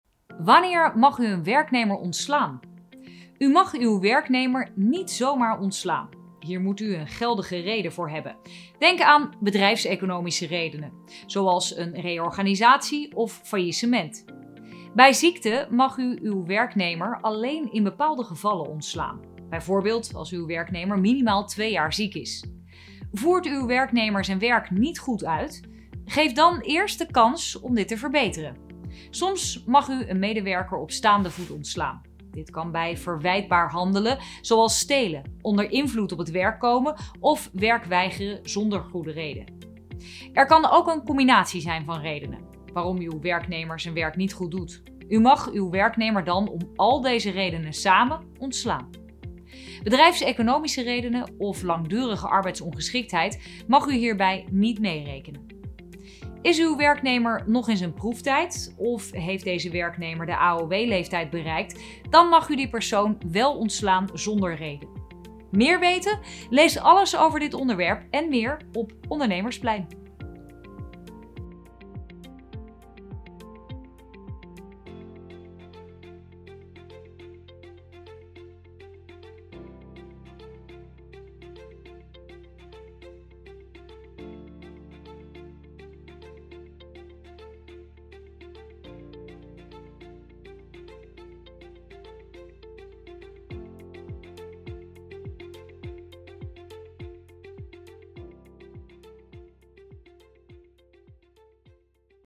Audiodescriptiebestand (mp3)